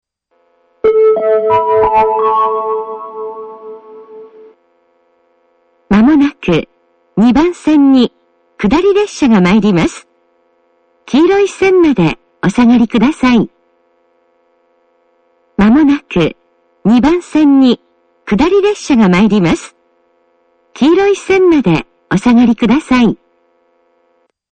この駅は放送・メロディーとも音量が非常に大きいです。
２番線接近放送